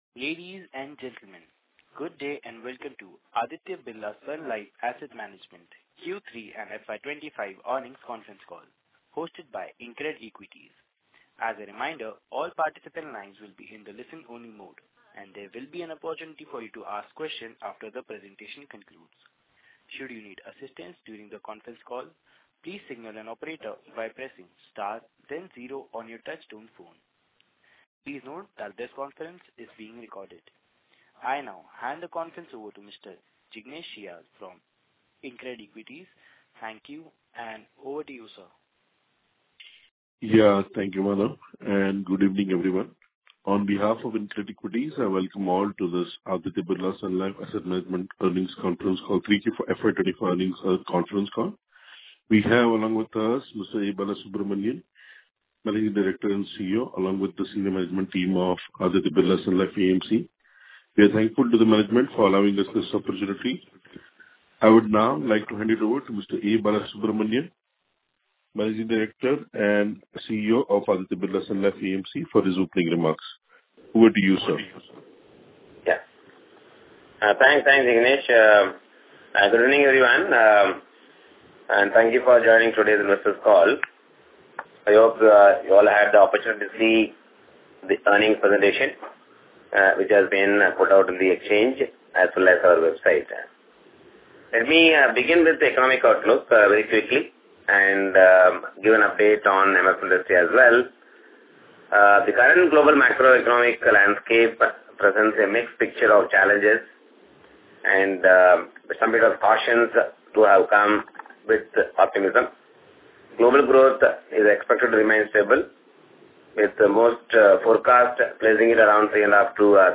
Concalls
concall_audio-q3_fy25-abslamc.mp3